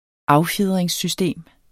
Udtale [ ˈɑwˌfjeðˀʁεŋs- ]